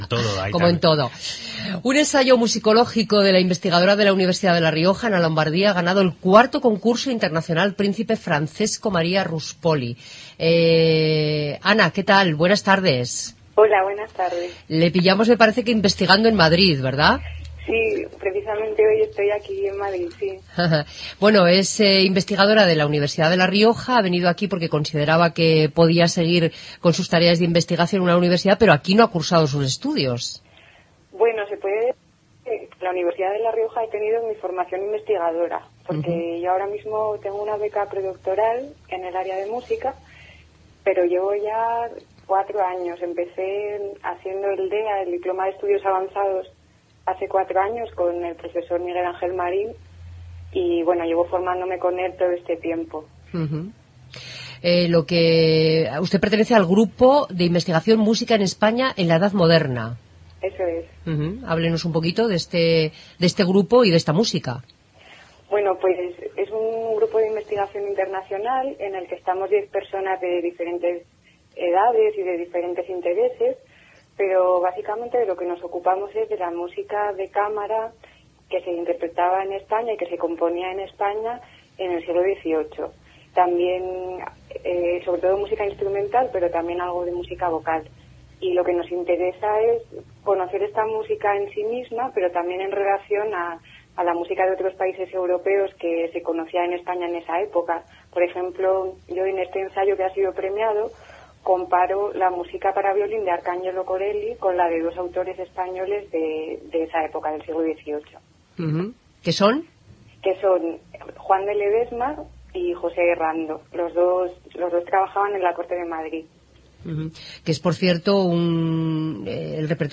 Entrevista]